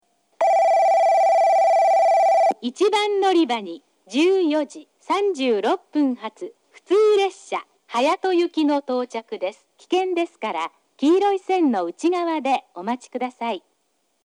放送はJACROS簡易詳細型で、接近ベルが鳴ります。
スピーカーはFPS平面波です。なお放送の音割れが激しいですがこれは元からで、夜間音量の方が綺麗に聞こえます。
1番のりば接近放送（隼人行き）
→臨時列車。隼人行きは、宮崎駅にあるトーンの低いパーツが使われています。